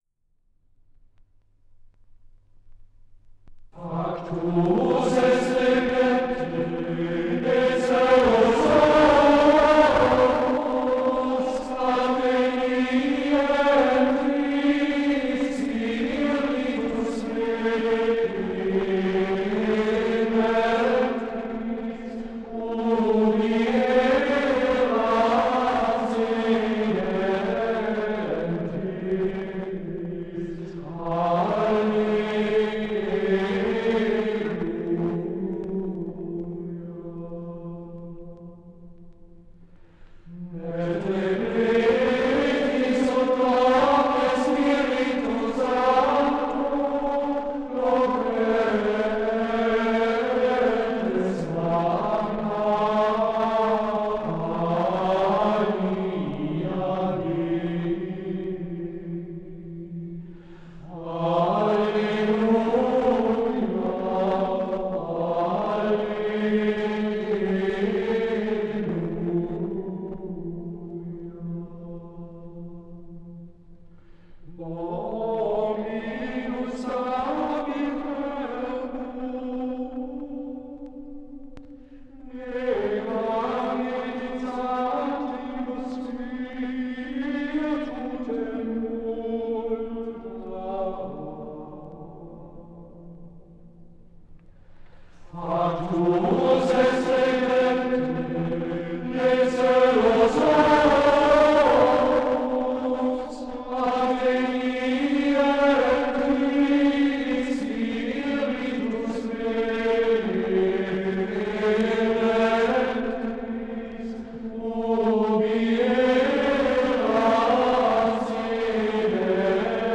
aufgenommen in der Klosterkirche Knechtsteden